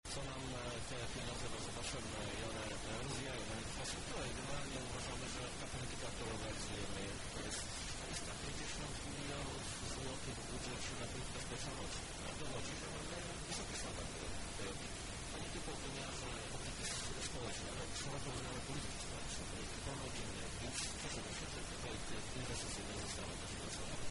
-mówi Krzysztof Żuk, prezydent miast Lublin